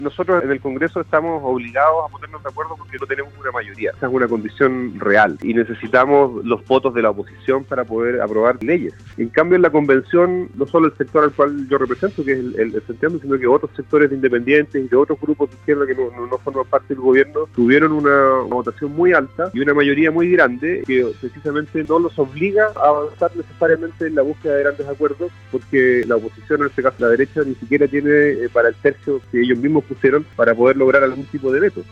El diputado del Distrito 26 y miembro de la Comisión de Medio Ambiente del organismo, Jaime Sáez, valoró en entrevista con Radio Sago el acuerdo alcanzado en la cámara, ya que fueron 105 los votos favorables, incluyendo a una buena parte de la oposición.